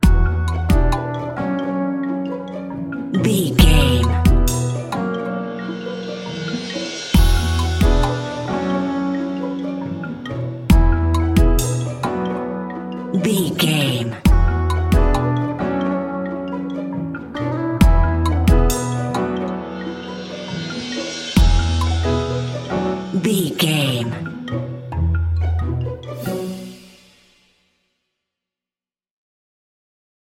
Mixolydian
percussion
strings
double bass
synthesiser
silly
circus
goofy
comical
cheerful
perky
Light hearted
quirky